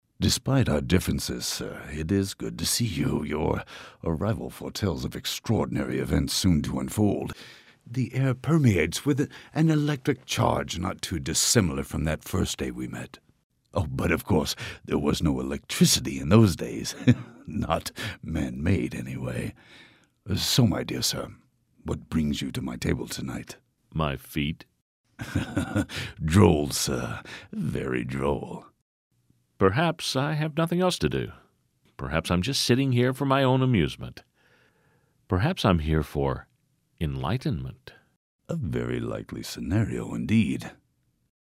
Tantalized. That's my word for how I feel after listening to the wonderful character voices everyone created for me.